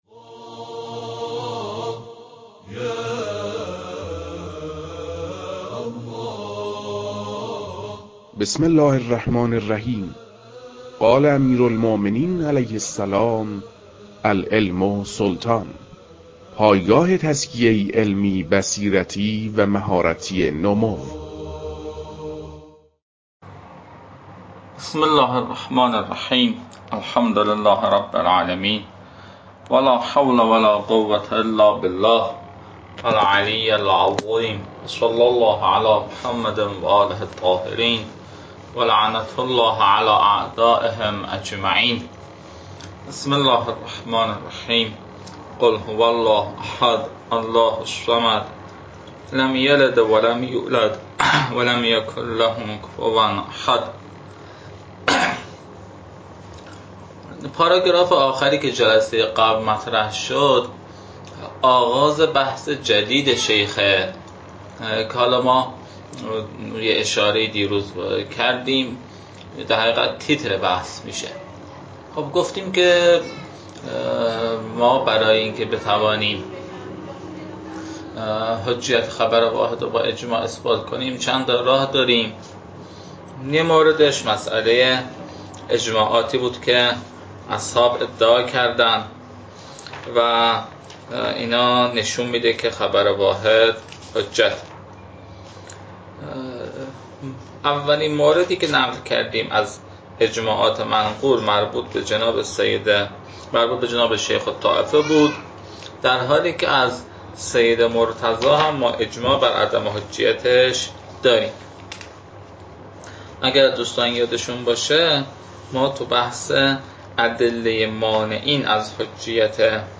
فایل های مربوط به تدریس مبحث رسالة في القطع از كتاب فرائد الاصول